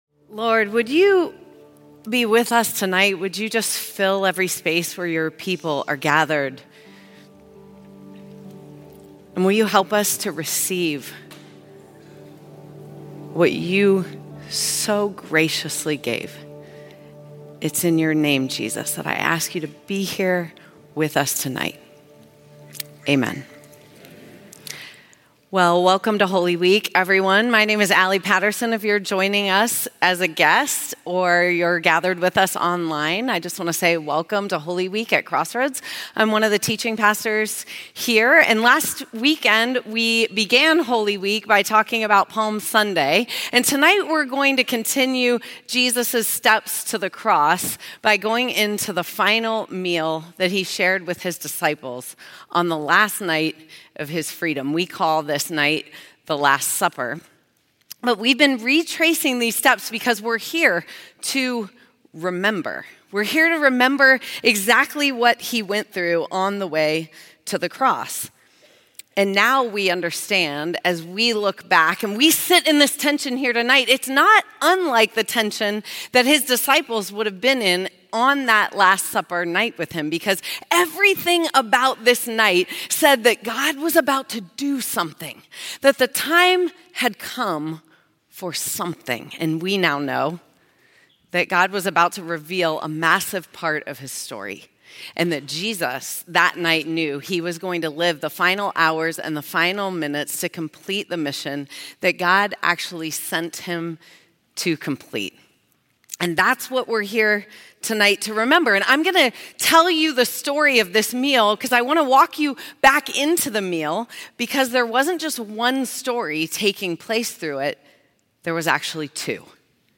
Holy Week Special Service